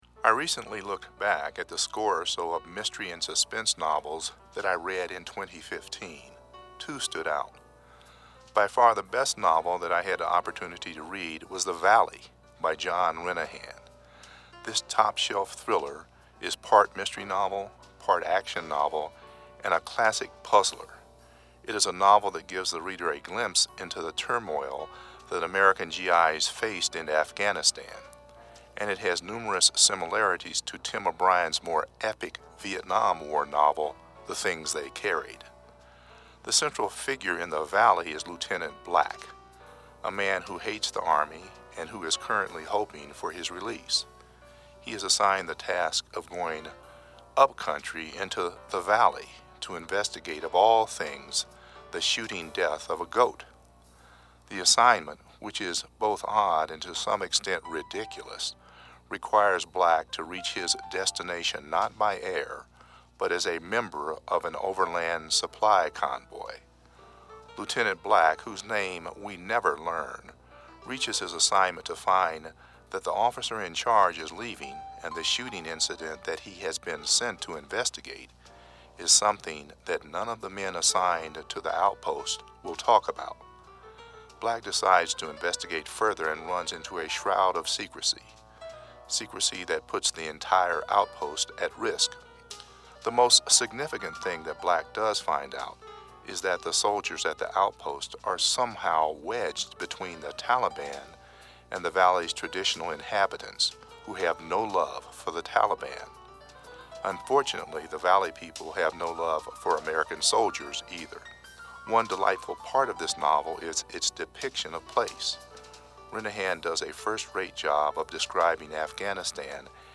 radio review